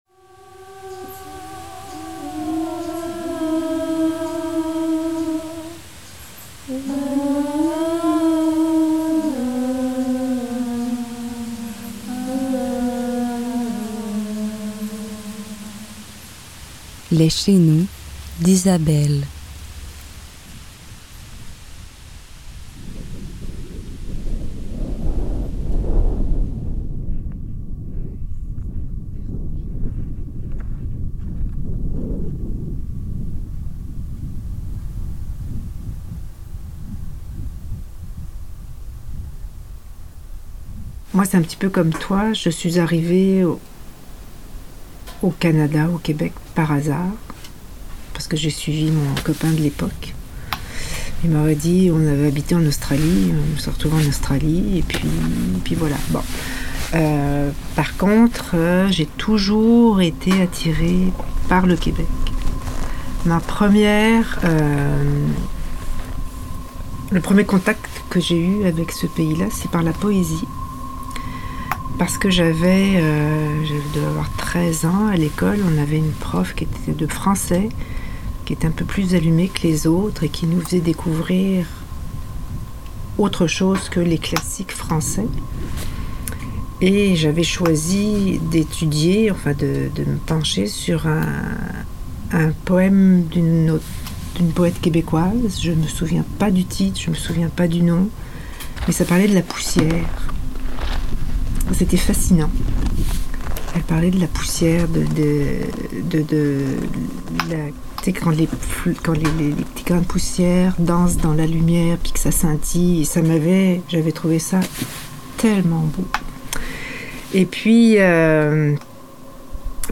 Extrait de la microhistoire
En conversation avec